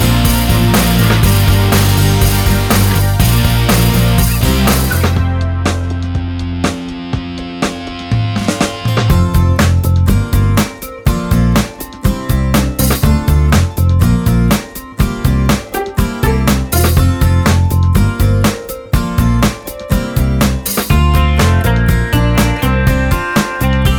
No Backing Vocals Irish 2:48 Buy £1.50